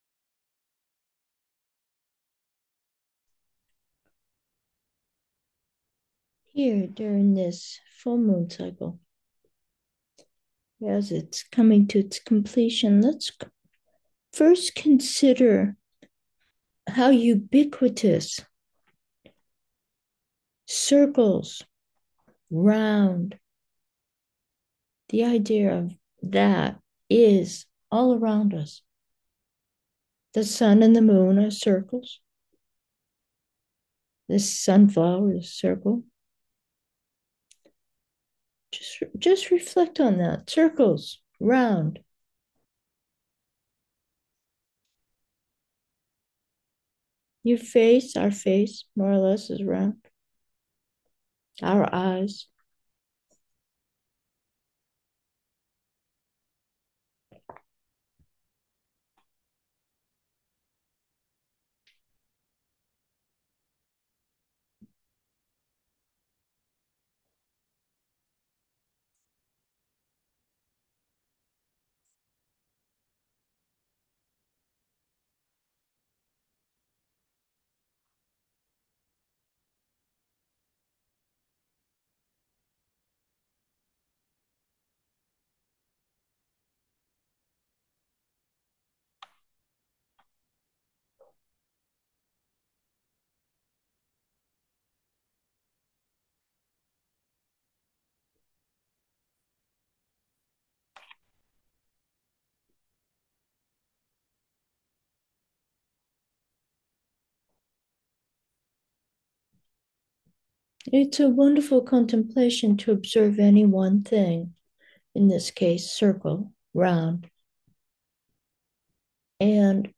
Meditation: observe, muse: round